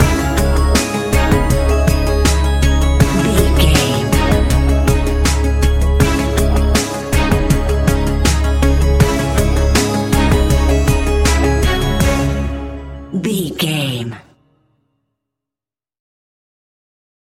Ionian/Major
C♯
electronic
techno
trance
synths
synthwave
instrumentals